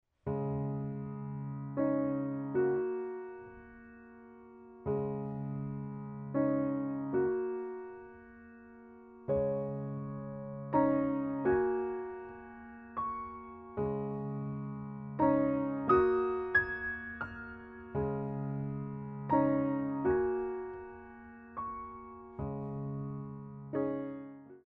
Reverence
6/8 - 64 with repeat